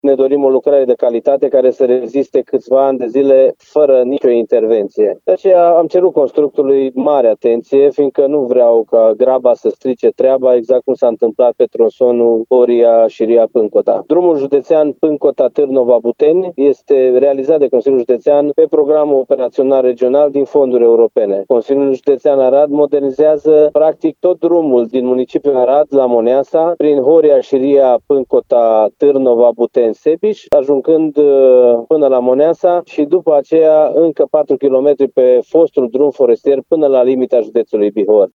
Constructorul a declarat că încearcă finalizarea lucrării până la sfârșitul anului, dar președintele Consiliului Județean Arad, Iustin Cionca, a cerut să se pună accentul pe calitate:
Iustin-Cionca-drum.mp3